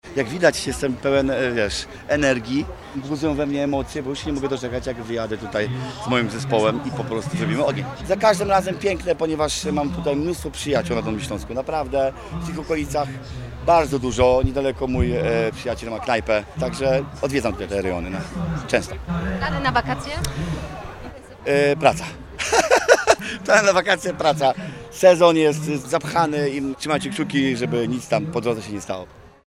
Zajrzeliśmy za kulisy chwilę przed występem.